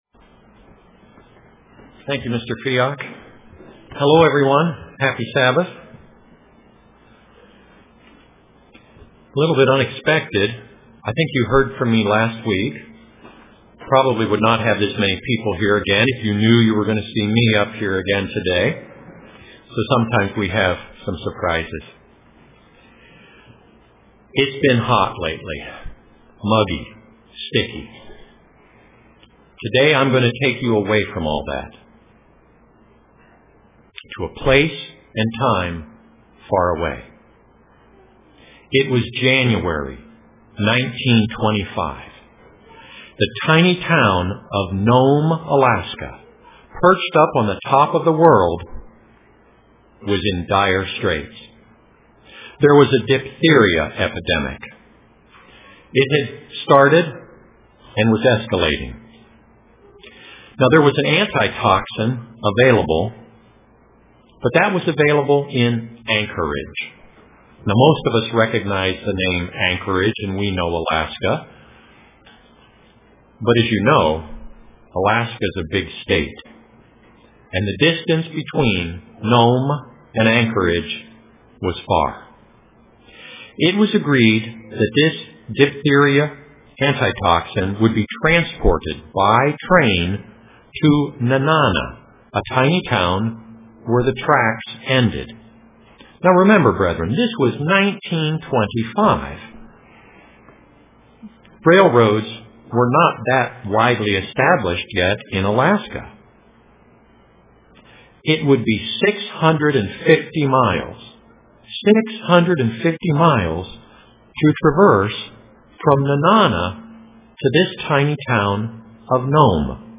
Print Balto UCG Sermon Studying the bible?